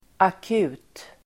Uttal: [ak'u:t]